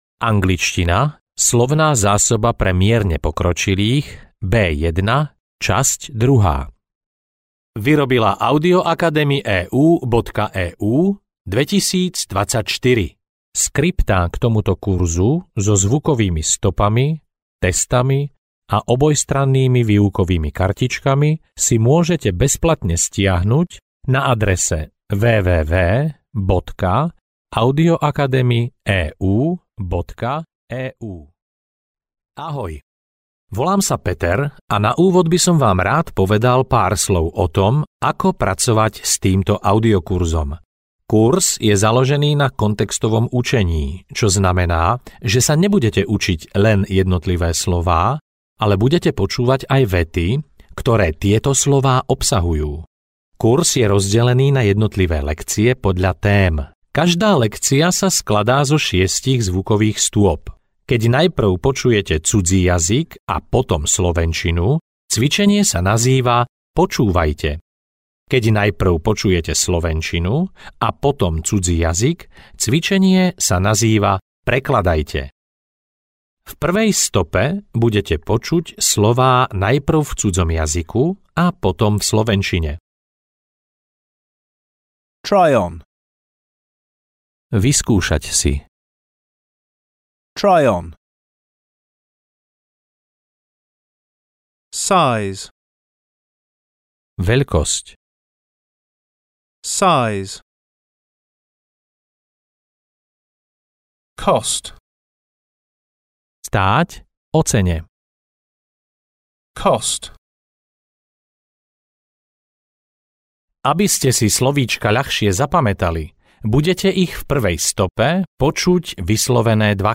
Angličtina pre mierne pokročilých B1 – Časť 2 audiokniha
Ukázka z knihy